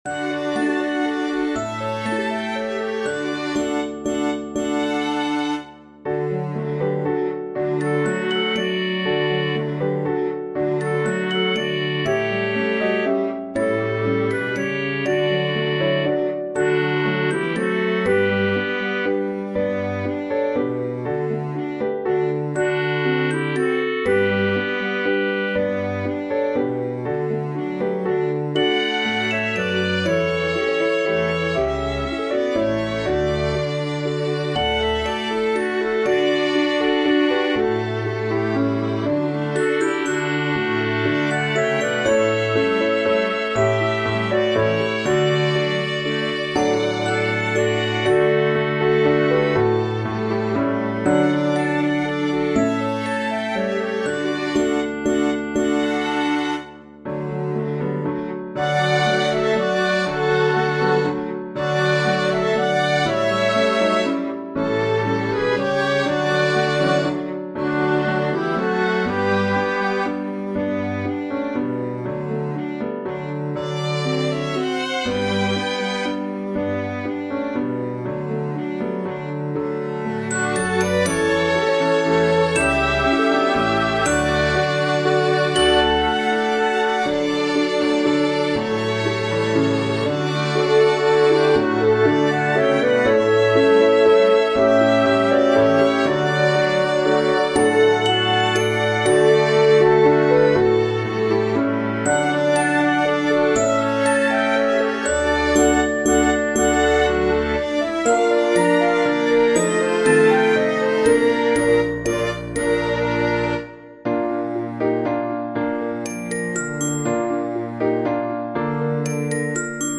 Normal balance between singers and orchestra:
stille_nacht_2019-orchestra.mp3